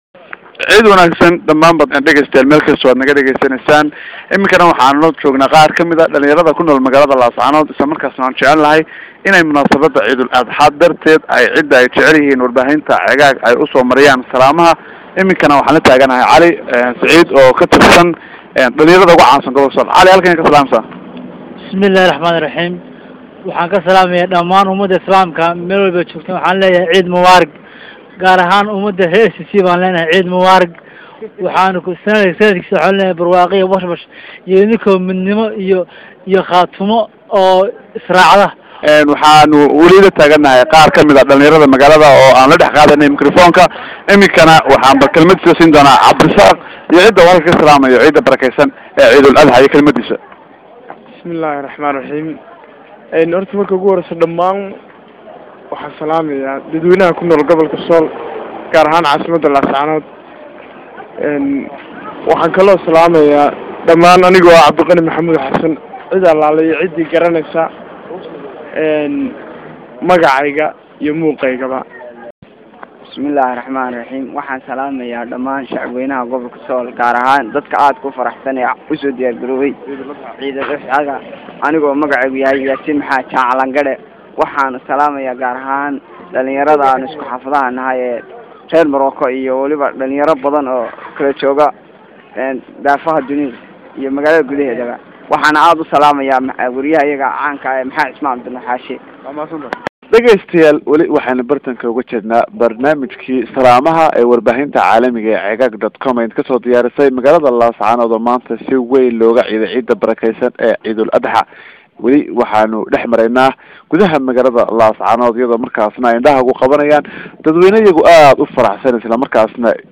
Salaamha Ciida ee reer Laascaanood hawada u marinayaan Asxaabtooda iyo qaraabadooda